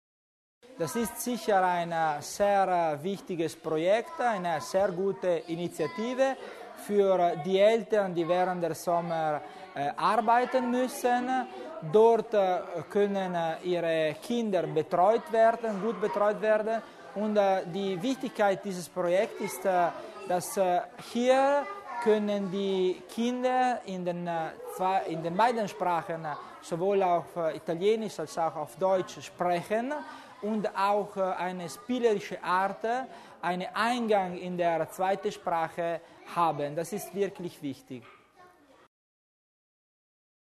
Landesrat Tommasini zur Bedeutung des Projekts